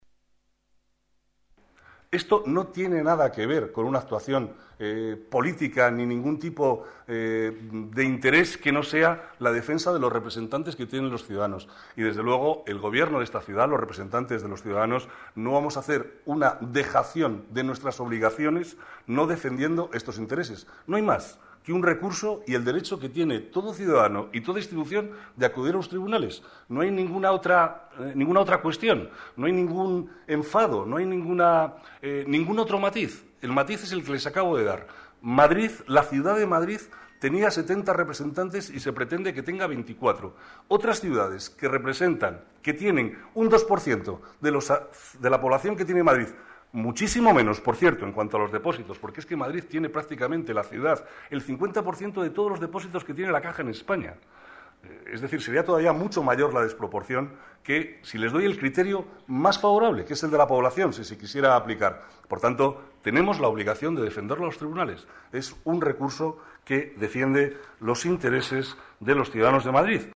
Nueva ventana:Declaraciones vicealcalde, Manuel Cobo: defensa de los intereses en Caja Madrid